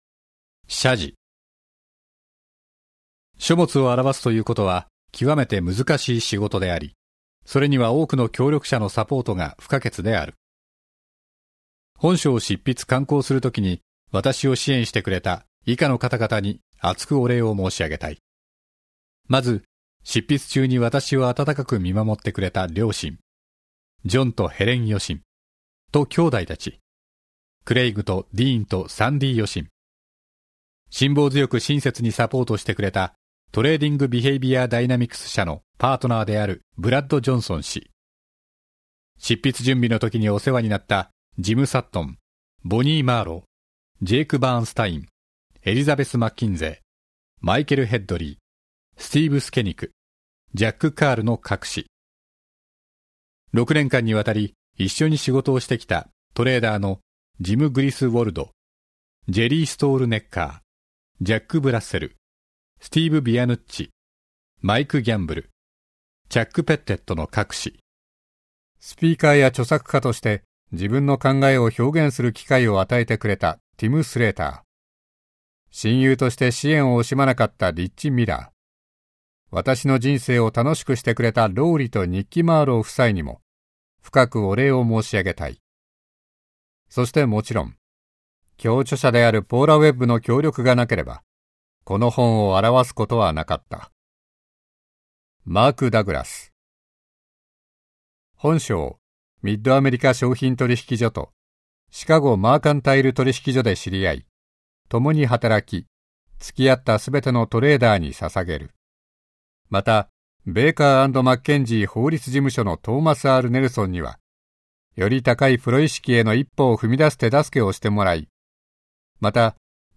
[オーディオブック] 規律とトレーダー 相場心理分析入門